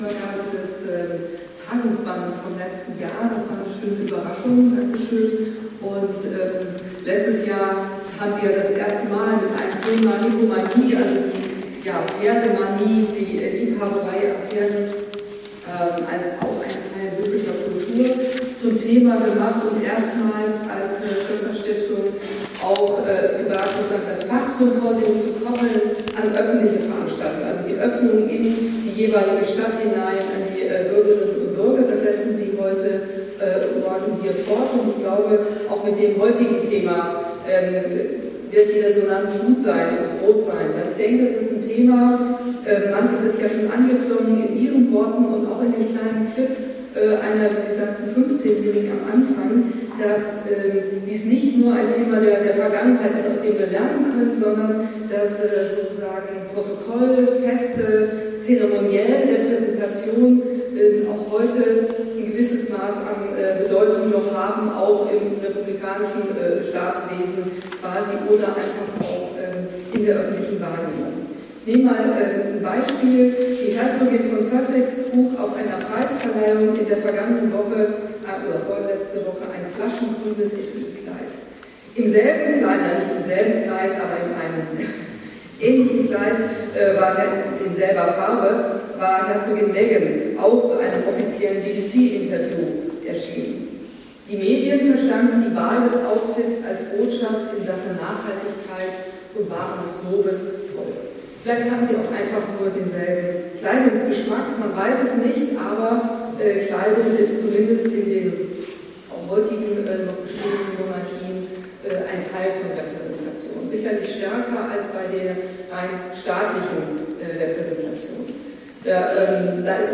Statt im großen Riesensaal von Schloss Sondershausen war das Symposium in den kleineren Blausen Salon verlegt worden.
Staatssekretärin für Kultur und Europa in der Thüringer Staatskanzlei Dr. Babette Winter, gleichzeitig auch Vorsitzende des Stiftungsrates, hielt das Grußwort. Im ersten Teil der Rede würdigte sie das Symposium und das sich das Schloss Sondershausen für die Bürger öffnet.
Rede Dr. Winter 1